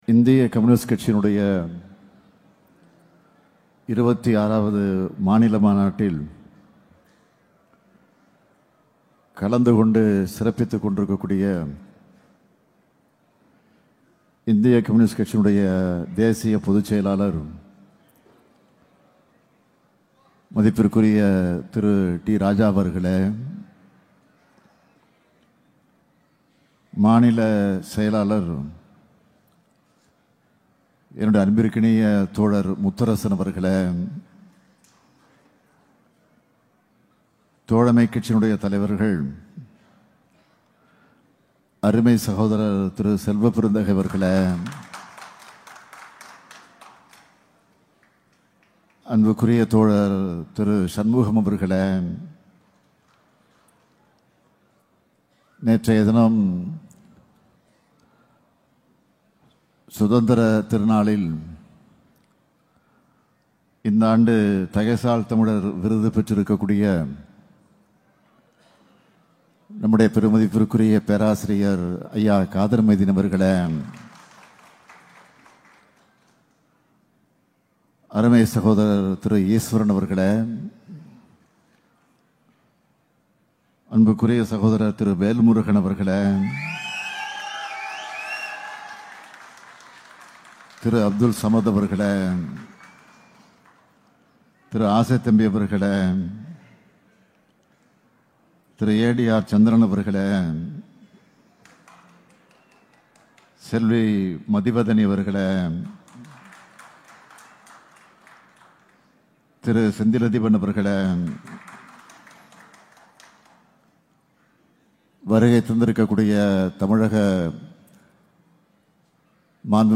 சேலத்தில் இந்தியக் கம்யூனிஸ்ட் கட்சி 26-வது மாநில மாநாட்டில் உரையாற்றிய முதல்வர் மு.க.ஸ்டாலின் அவர்கள், வருகிற 2026 ஆம் ஆண்டு தேர்தலிலும் எங்கள் கூட்டணிதான் வெற்றி பெறும் என்றும், திராவிட மாடல் ஆட்சியில் இந்தியாவுக்கே வழிகாட்டுகிற சாதனைகளைப் படைப்போம் என்றும் குறிப்பிட்டார்.
முதலமைச்சர் மு.க.ஸ்டாலின் அவர்கள் ஆற்றிய உரை வருமாறு:-